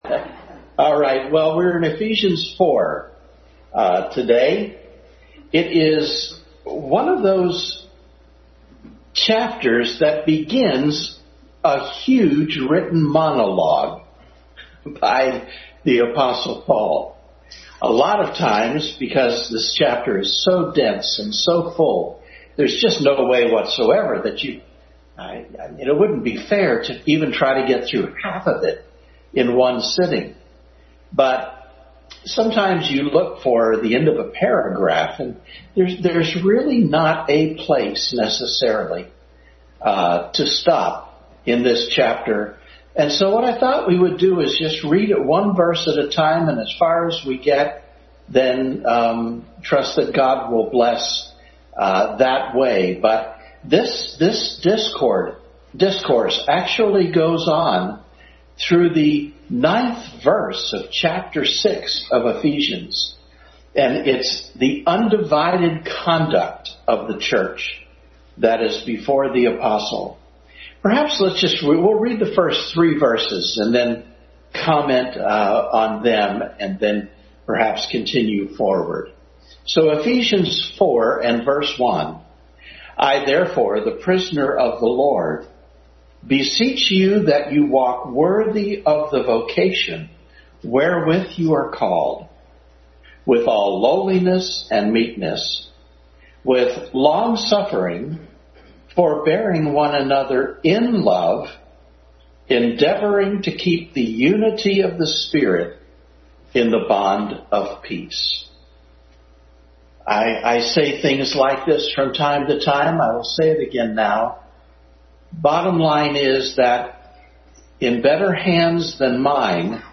Adult Sunday School continued study in Ephesians.